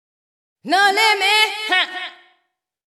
House / Voice / VOICEGRL164_HOUSE_125_A_SC2.wav